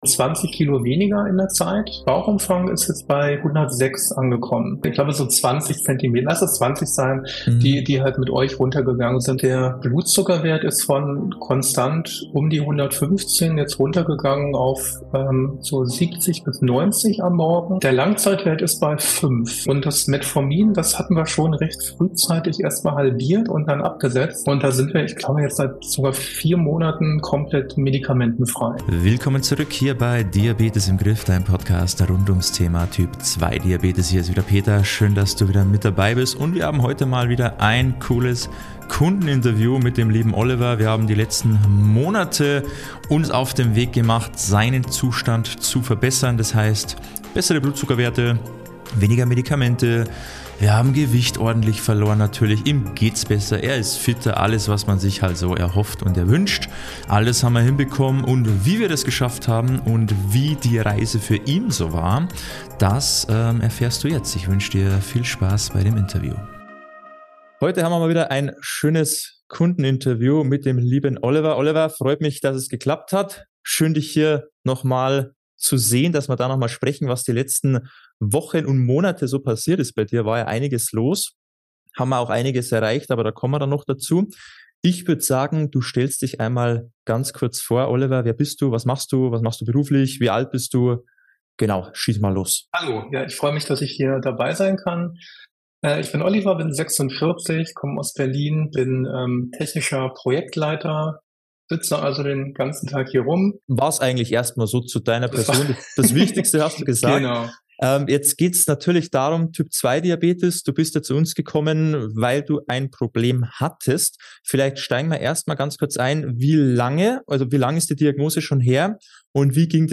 #216 Trotz Kohlenhydrate wieder einen Blutzuckerwert im Normbereich - Kundeninterview